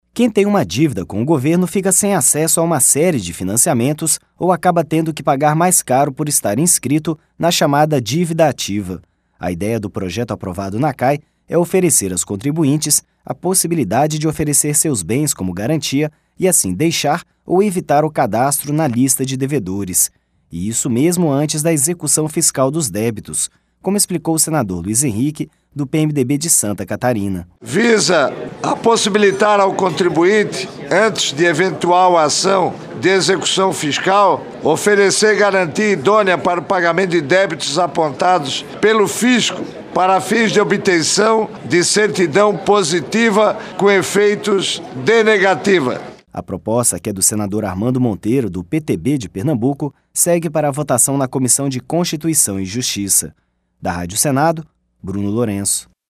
A ideia do projeto aprovado na CAE é oferecer aos contribuintes a possibilidade de oferecer seus bens como garantia e assim deixar ou evitar o cadastro na lista de devedores. E isso mesmo antes da execução fiscal dos débitos, como explicou o senador Luiz Henrique, do PMDB de Santa Catarina.